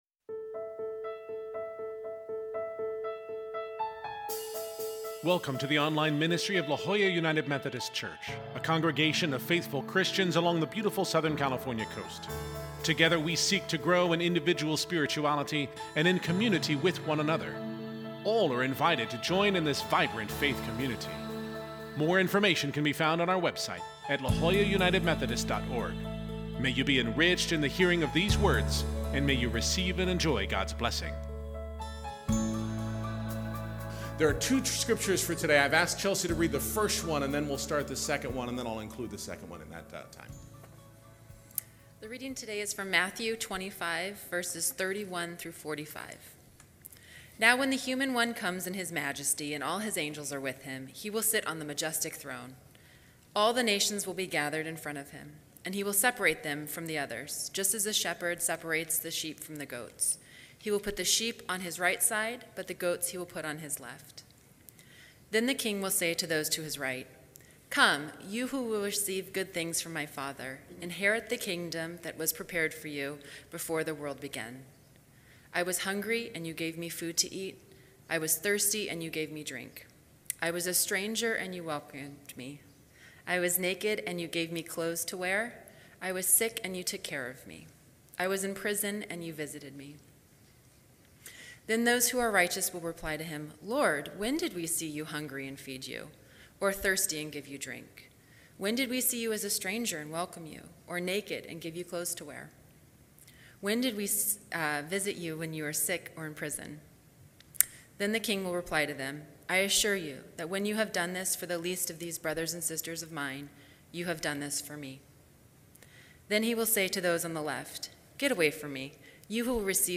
In this week’s message, we will consider the idea of eternal life, heaven, and who it’s for…